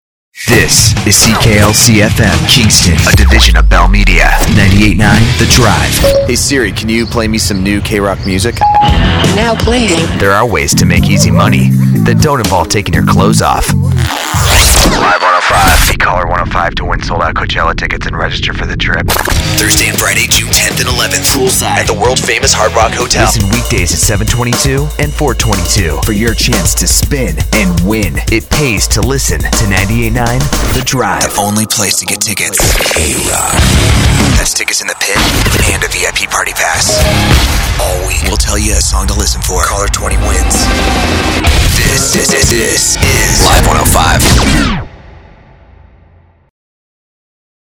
Imaging Voice Demos: